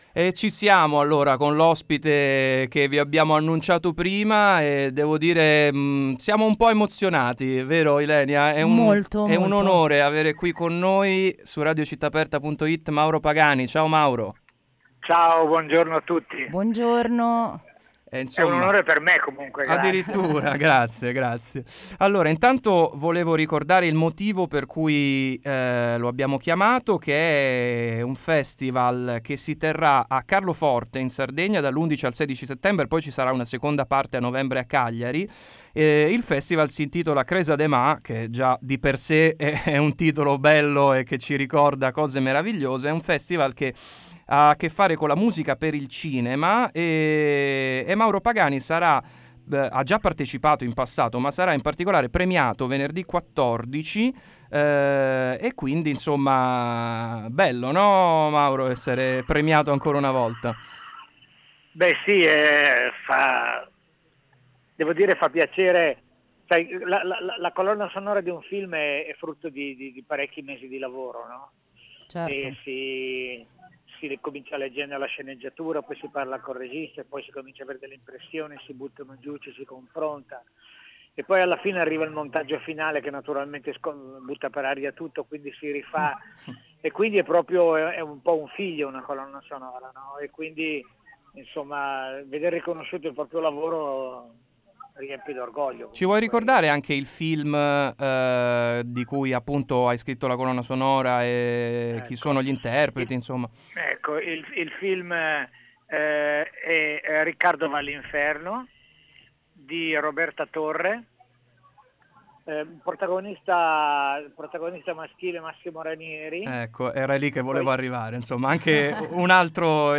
Intervista a Mauro Pagani 10/9/2018 | Radio Città Aperta